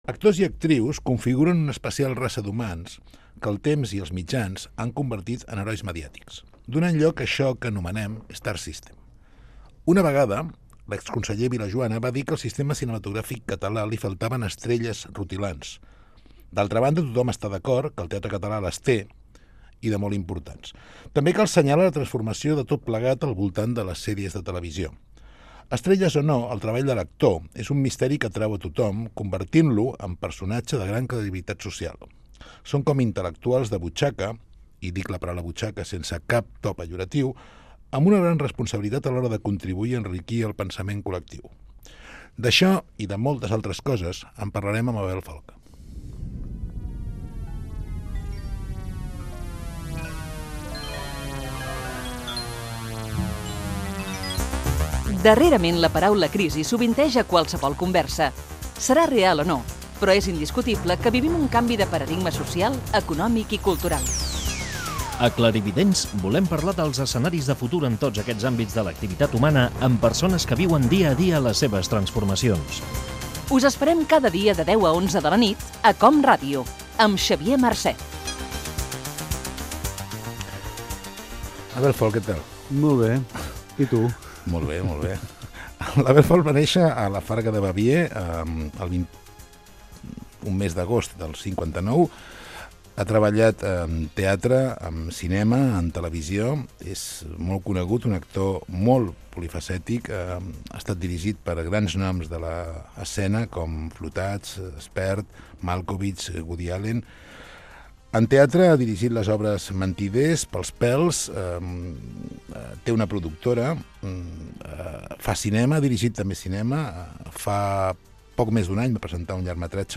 Comentari sobre el teatre català i el treball de l'actor. Careta del programa. Fragment d'una entrevista a l'actor Abel Folk
Fragment extret de l'arxiu sonor de COM Ràdio.